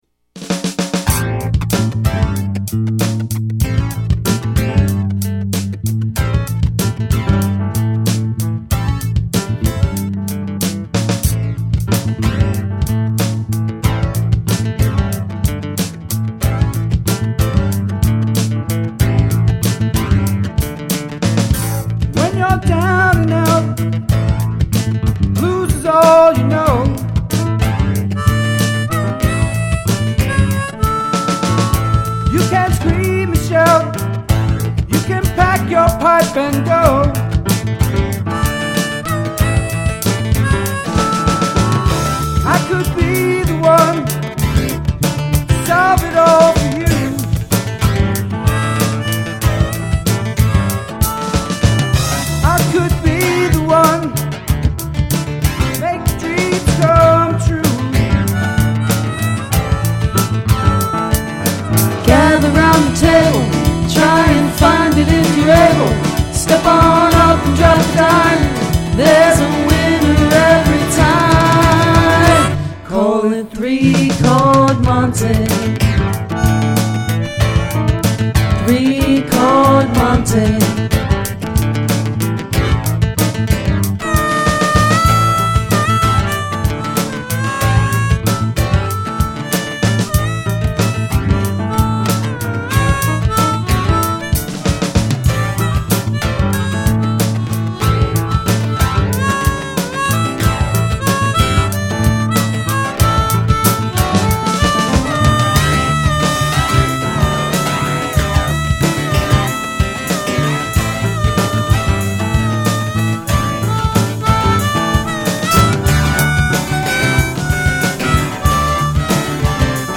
Rehearsal - March 19, 2007
Guitar
Roland Drums
Bass
Keyboard
Mandolin, Fiddle, Harmonica
backing vocal